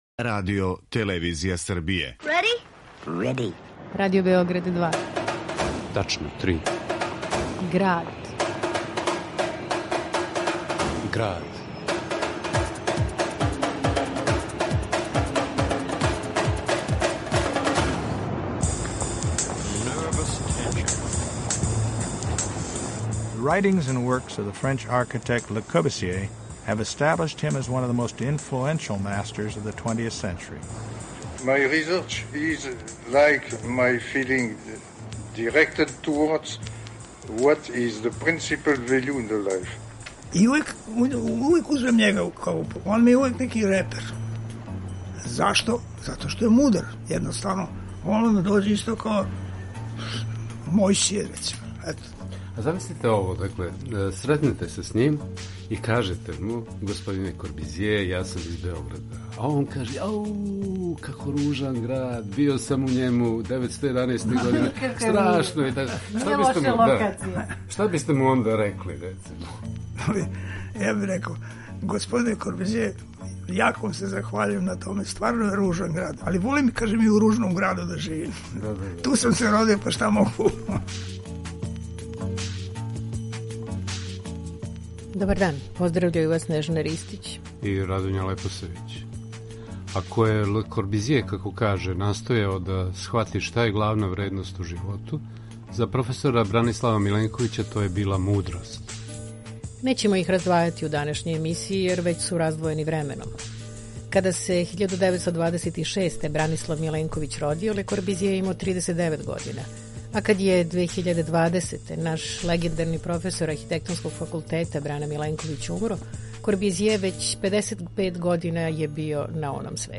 на архивским снимцима